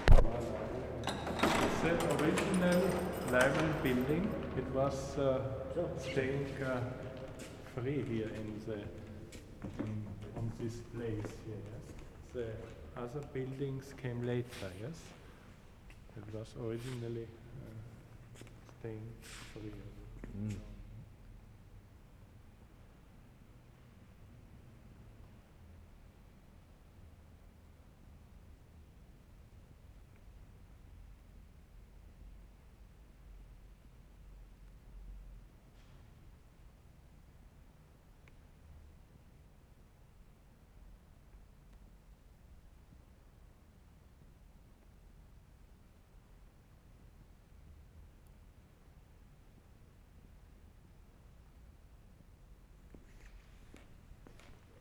5 - 11. SIRENS (5-6 police siren, 7-11 fire sirens).
7 - 11. All recorded inside garage.